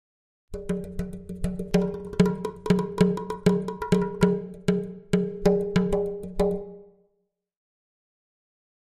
Drums Percussion Beat Version B - Additional Percussion